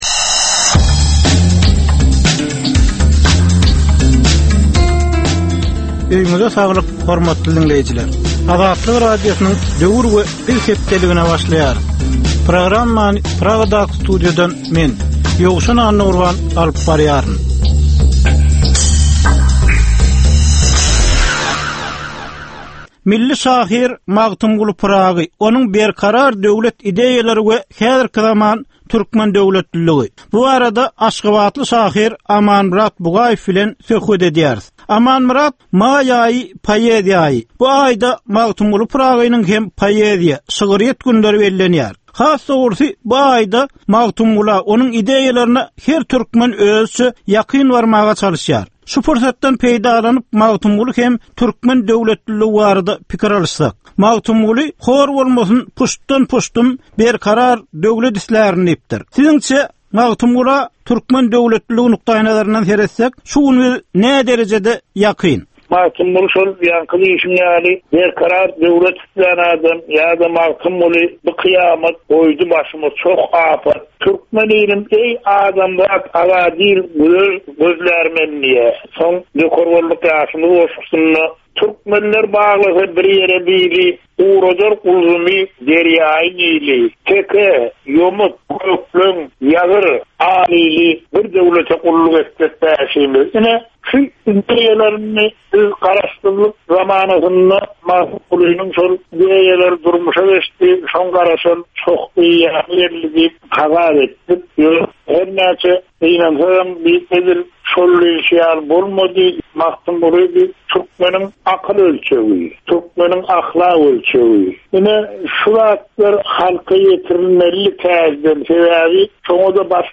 Türkmen jemgyýetindäki döwrüň meseleleri. Döwrüň anyk bir meselesi barada ýörite syn-gepleşik. Bu gepleşikde diňleýjiler, synçylar we bilermenler döwrüň anyk bir meselesi barada pikir öwürýärler, öz garaýyşlaryny we tekliplerini orta atýarlar.